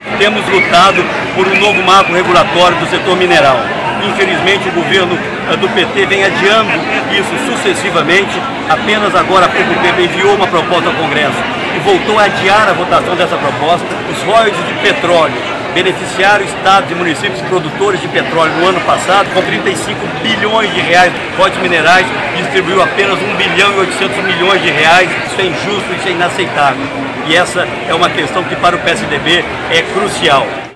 Em encontro do PSDB realizado em Belém do Pará, Aécio voltou a defender a revisão dos royalties do minério.
Fala do senador Aécio Neves: